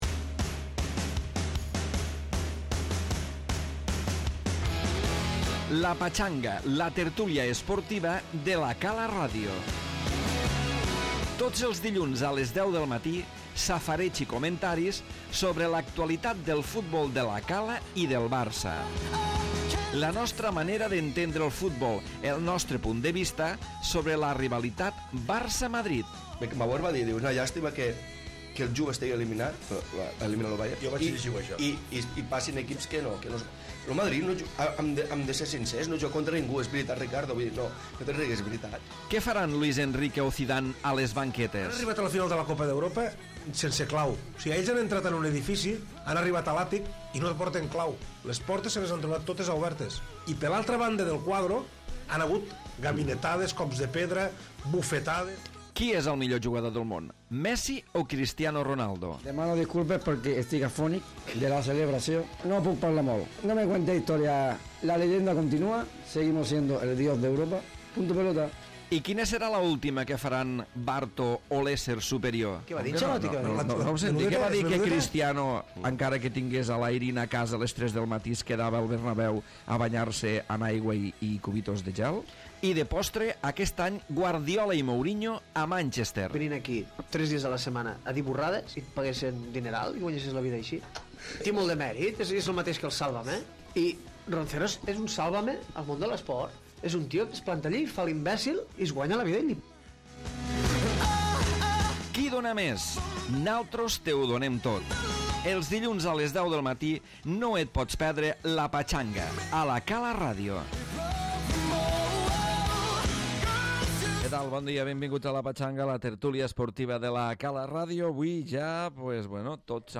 Tertúlia habitual en l'àmbit futbolístic que repassa d'inici l'actualitat del 1r equip de futbol, i per tant, aquesta setmana, la derrota al camp del Remolins Bítem per 5 a 0 i a l'hora analitza l'actualitat del Barça i el Reial Madrid.